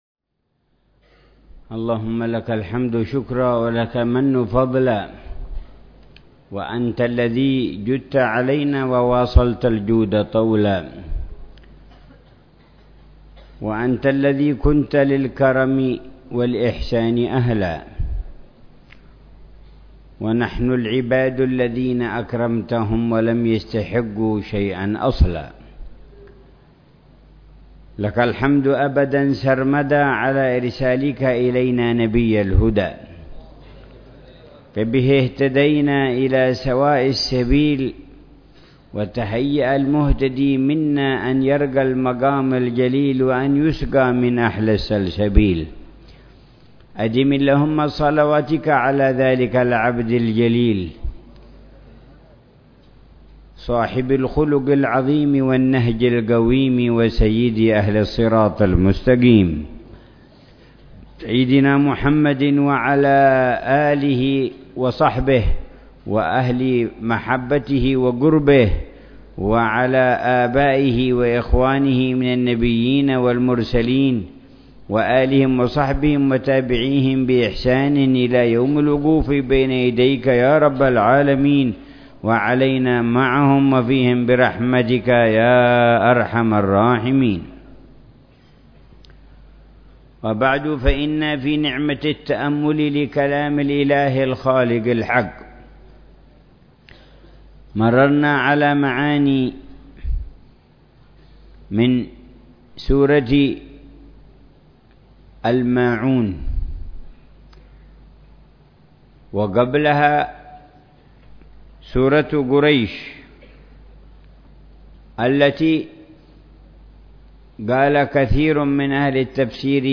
تفسير الحبيب عمر بن محمد بن حفيظ لسورة الفاتحة وقصار السور بدار المصطفى ضمن دروس الدروة الصيفية العشرين في شهر رمضان المبارك من العام 1435هـ.